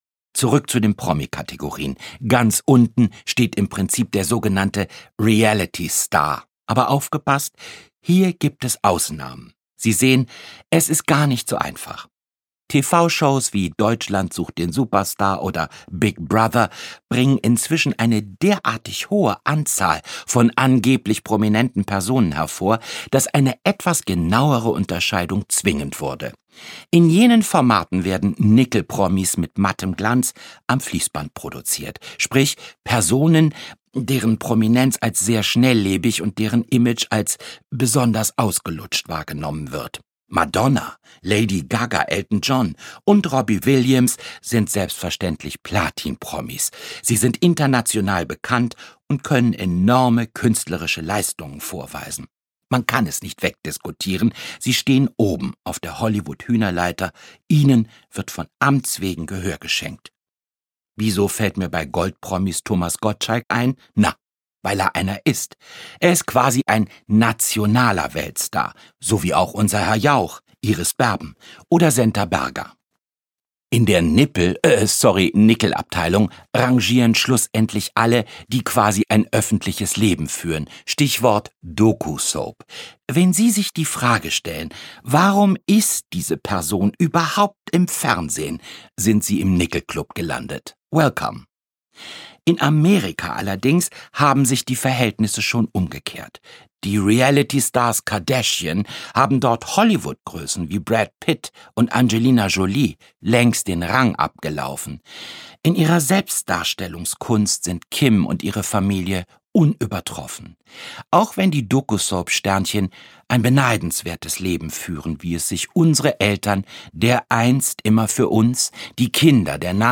Frisch hapeziert - Hape Kerkeling - Hörbuch